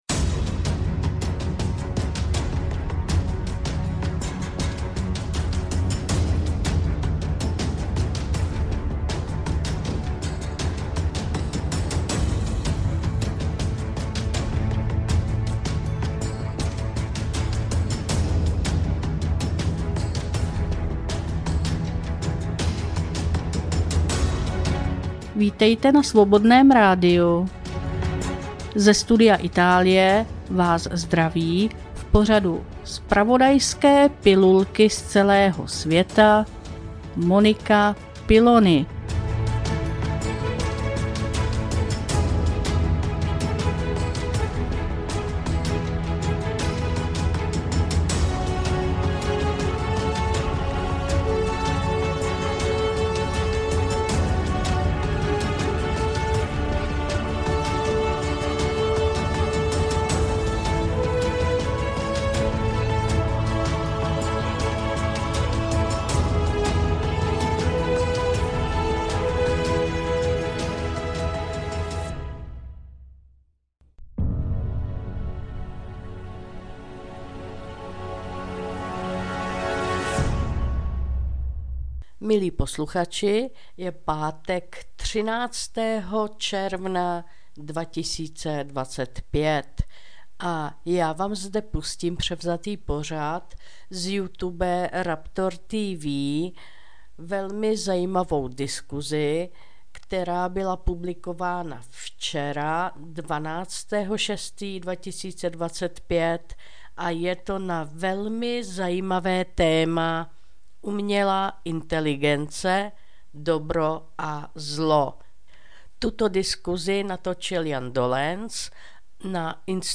2025-06-13 - Studio Itálie - Umělá inteligence: Dobro a zlo. Diskuzní pořad Institutu české levice.